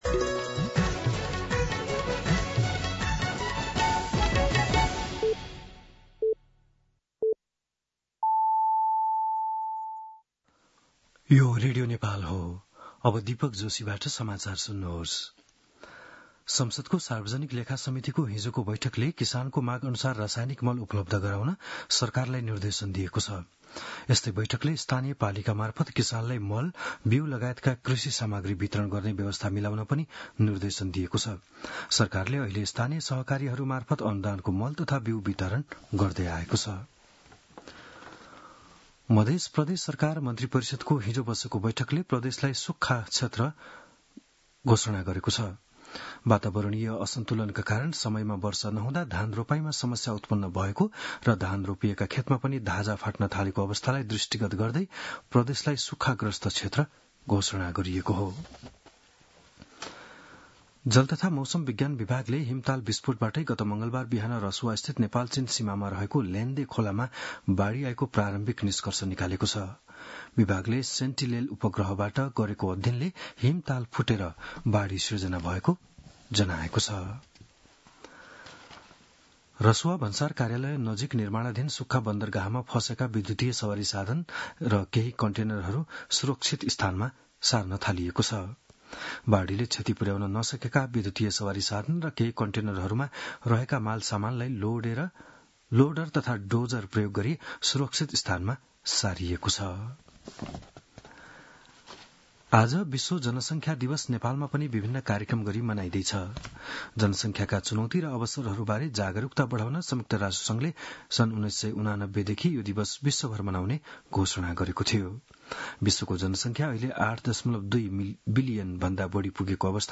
बिहान ११ बजेको नेपाली समाचार : २७ असार , २०८२